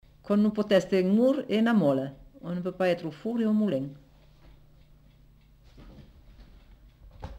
Aire culturelle : Comminges
Lieu : Cathervielle
Type de voix : voix de femme
Production du son : récité
Classification : proverbe-dicton